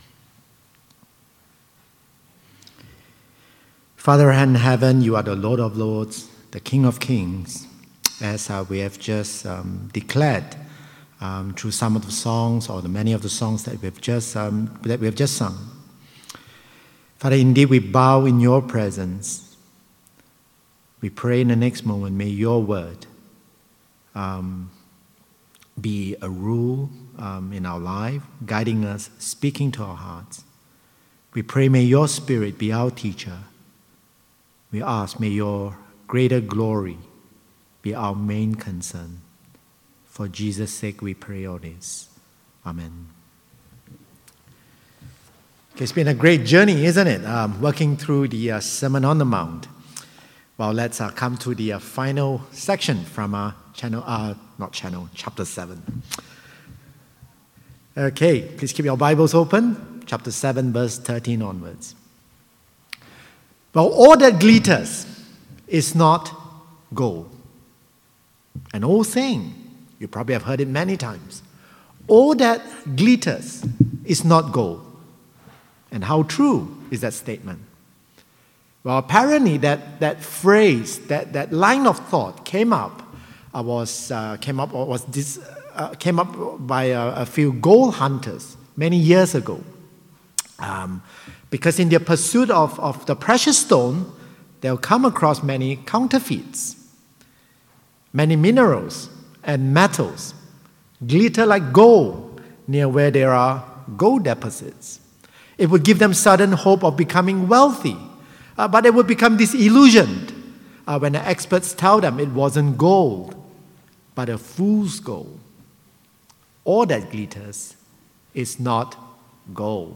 2015 Listening to Jesus on…True Foundation Preacher